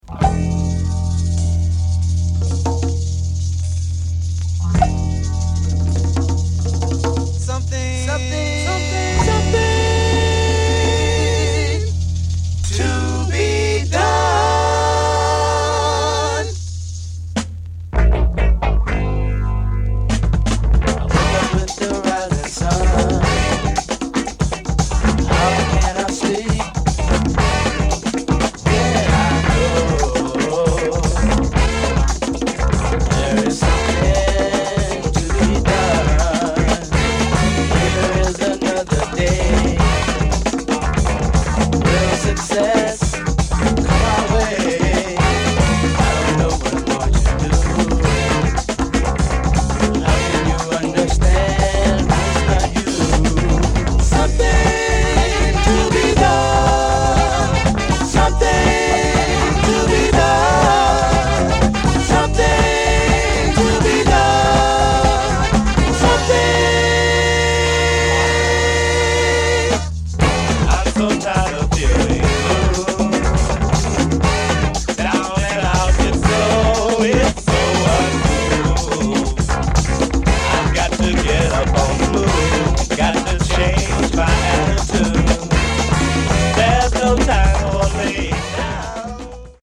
Obscure soul heaven.